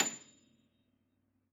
53h-pno29-A6.aif